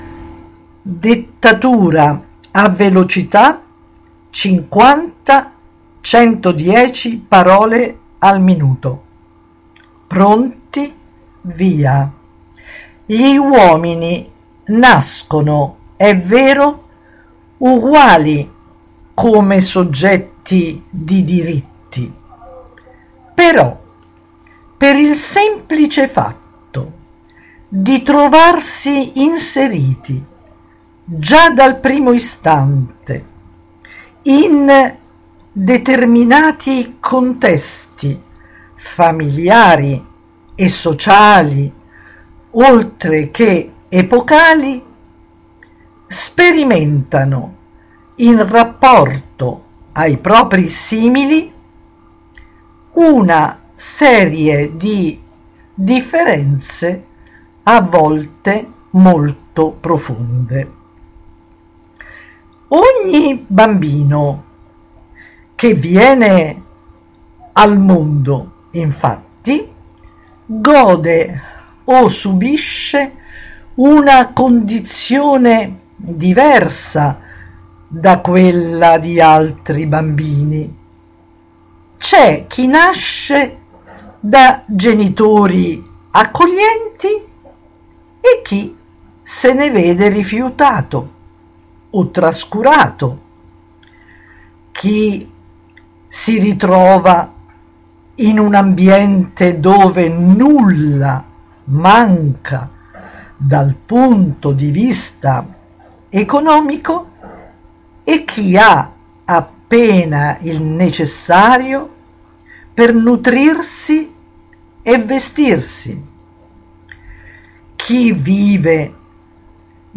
XIV Dettatura a velocità - 50/110 parole al minuto.
DettaturaAVelocita.mp3